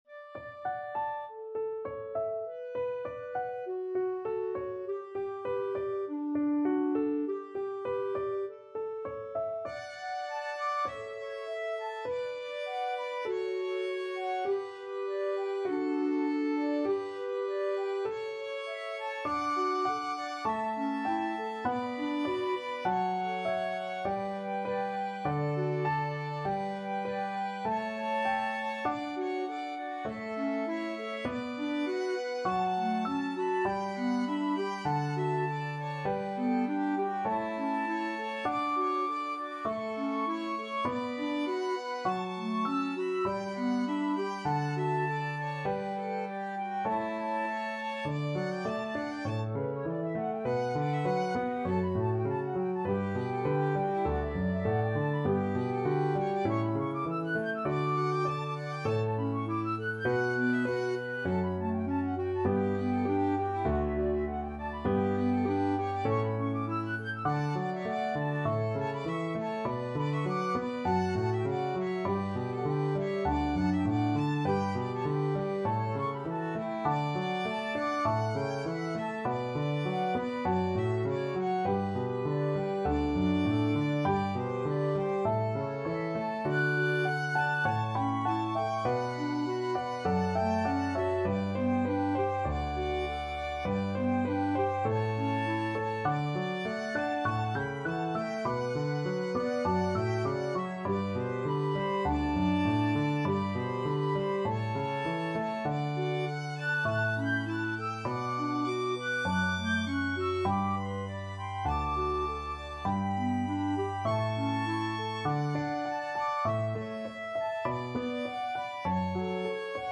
Classical
Flute
Violin